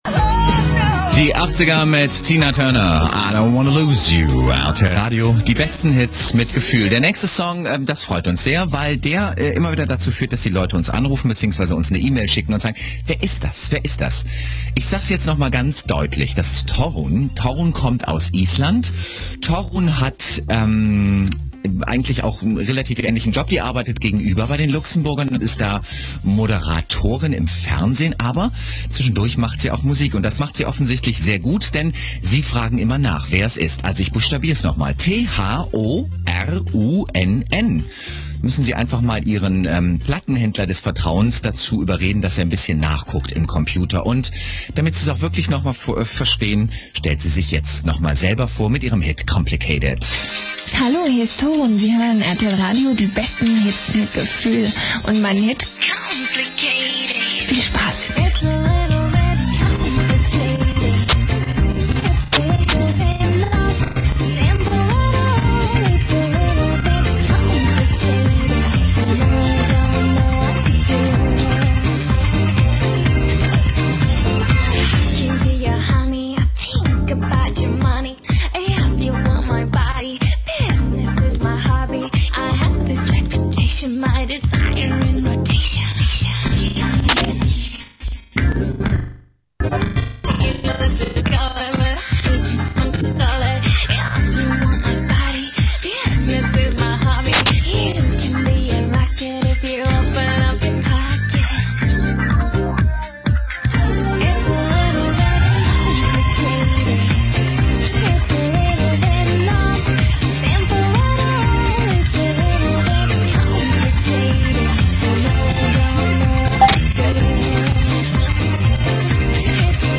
Bei einigen Rapporten habe ich kurze Empfangsdemos als mp3PRO erstellt. So kann man sich ein genaueres Bild über die Klangqualität machen.